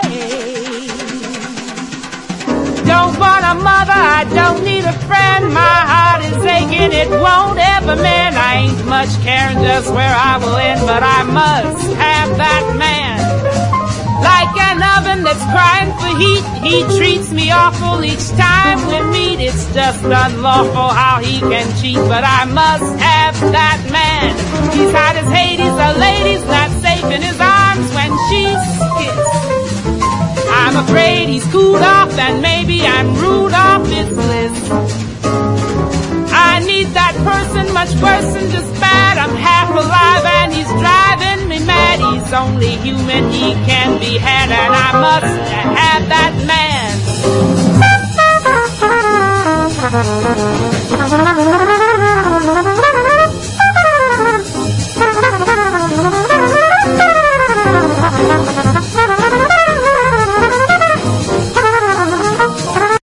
エレピが転がり軽やかにスウィングする名カヴァーで男の色気たっぷりの歌声も超スウィート。